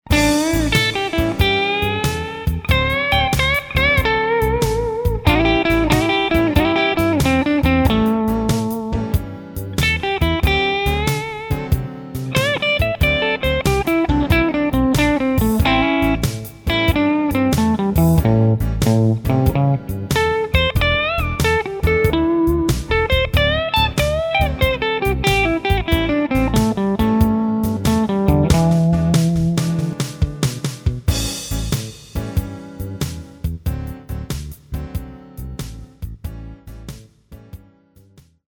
Tutto il solo, molto semplice data la sua natura prettamente didattica, è basato sulla scala pentatonica minore di A, ed è opportuno oservare come questa ben si adatti a tutti gli accordi del brano.
base del brano completa di solo o la